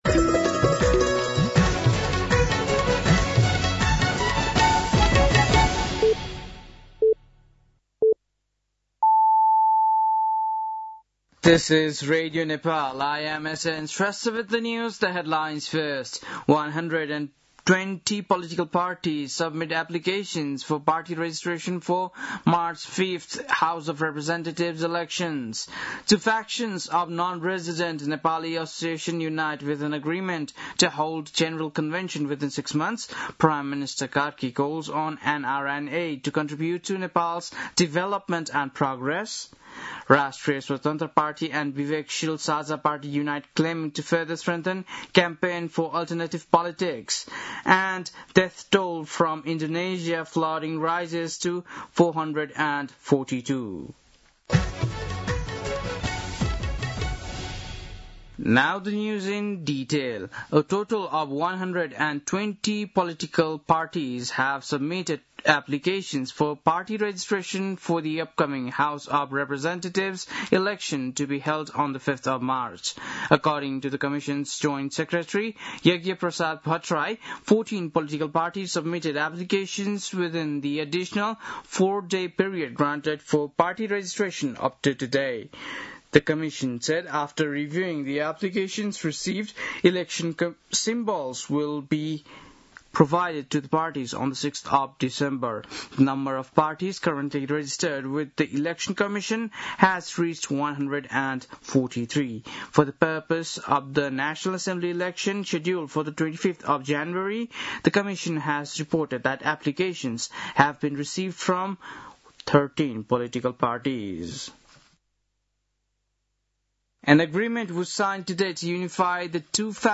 बेलुकी ८ बजेको अङ्ग्रेजी समाचार : १४ मंसिर , २०८२
8.-pm-english-news-.mp3